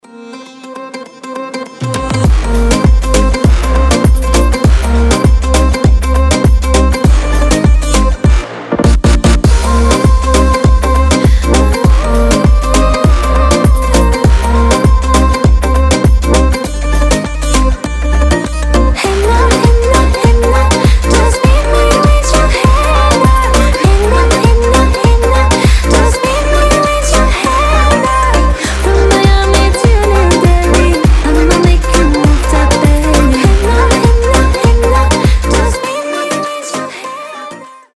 гитара
ритмичные
женский голос
Dance Pop
восточные
Заводная танцевальная поп-музыка